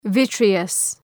Προφορά
{‘vıtrıəs}